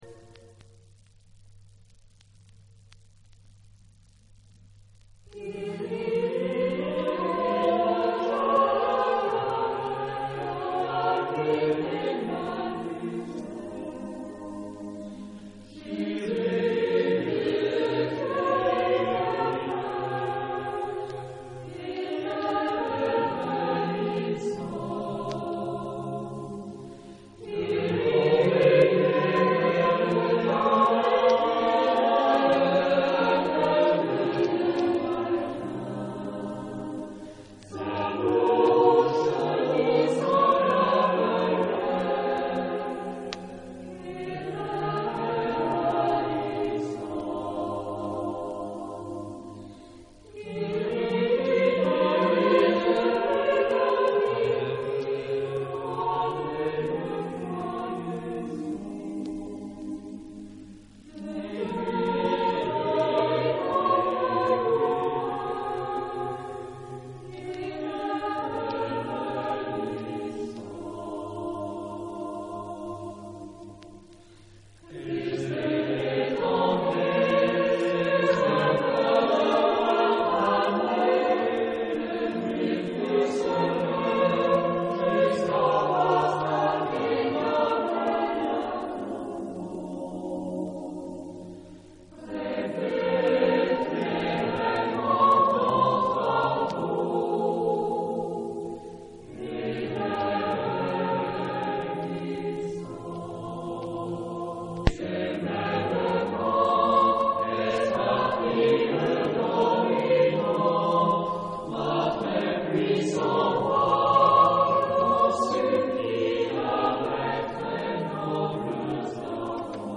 Tipo de formación coral: SATB  (4 voces Coro mixto )
Tonalidad : la menor